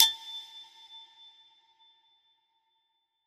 PERC - PILLS.wav